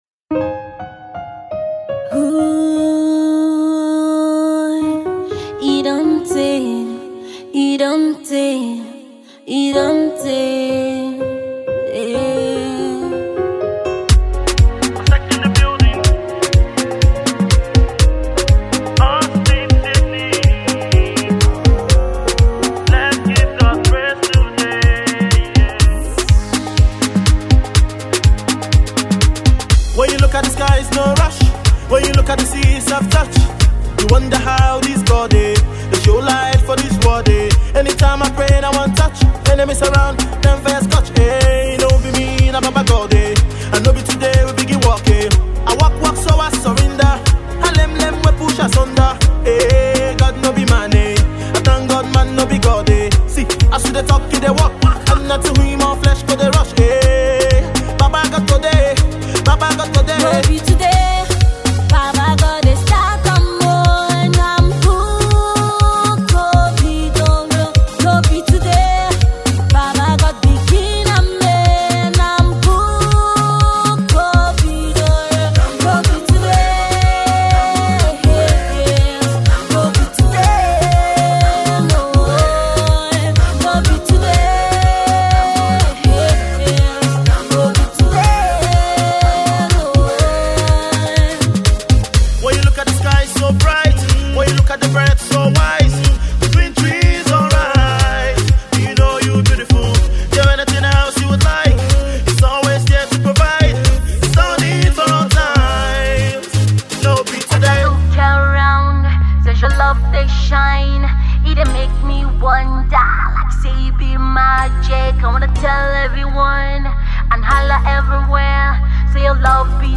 gospel hip hop containing melodious lyrics